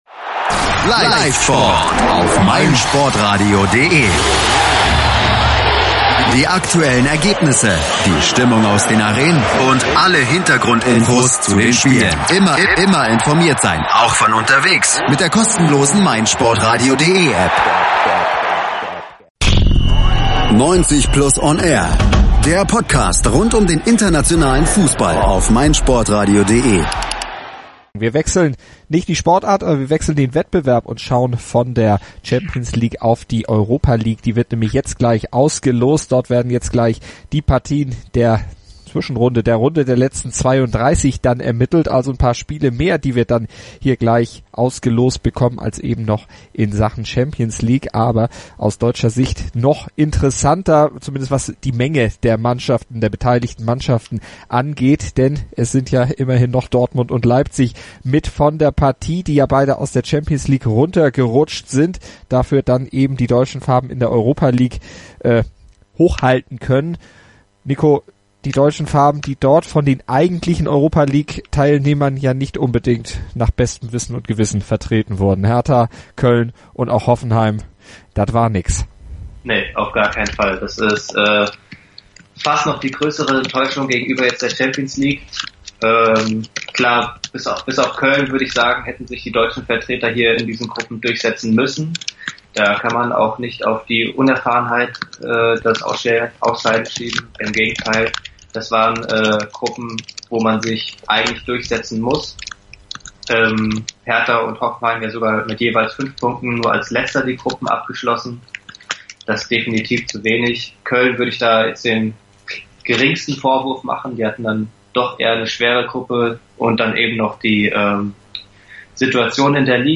Hört hier das Relive der Auslosungsübertragung.